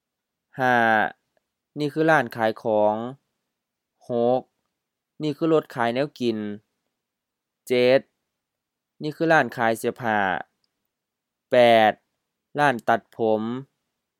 Basic vocabulary — Page 24 — Series A, pictures 05-08: shop/store, food stall, clothes, hairdresser
ล้านขายของ la:n-kha:i-khɔ:ŋ HF-M-M ร้านขายของ shop, store
ล้านตัดผม la:n-tat-phom HF-M-M ร้านตัดผม hairdresser's, barber shop